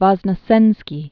(vŏznə-sĕnskē), Andrei 1933-2010.